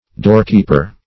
Doorkeeper \Door"keep`er\, n.